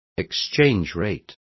Also find out how cotizacion is pronounced correctly.